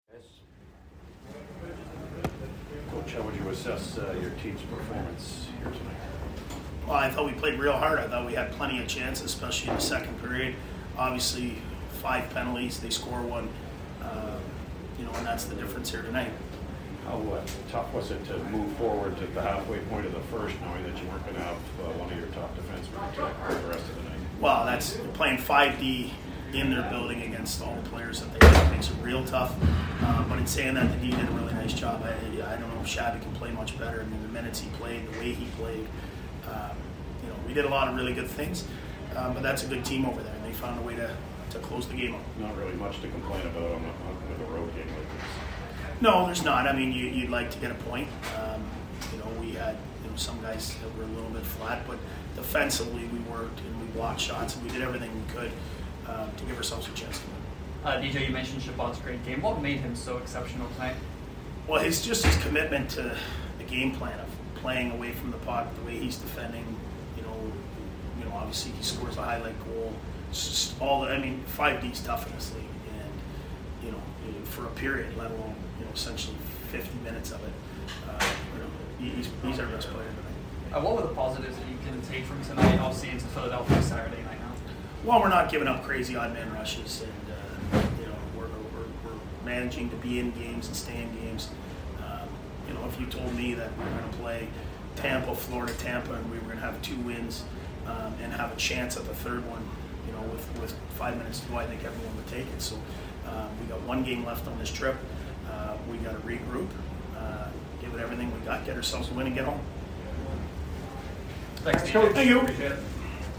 Post Game